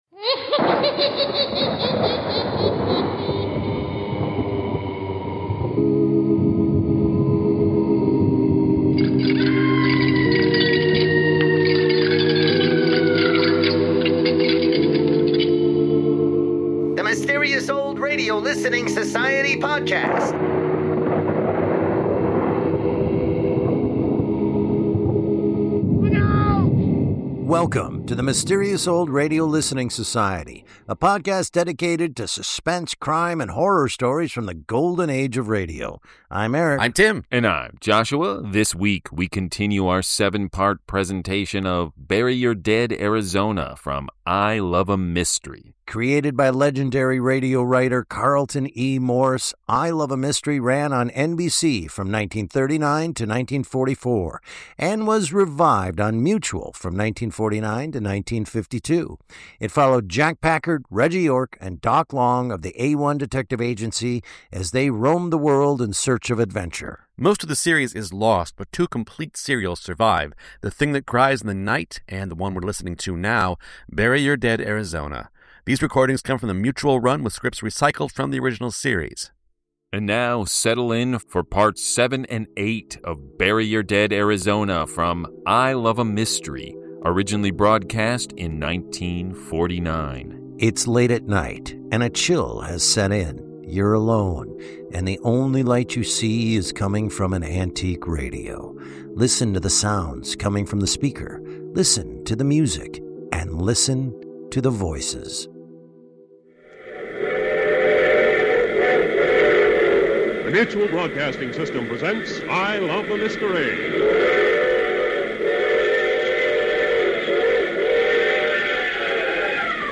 In parts 7 and 8 Jack, Reggie, and Doc are searching the Arizona desert at night, despite the sounds of screams, wolves, and gunshots!